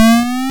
BumperHigh.wav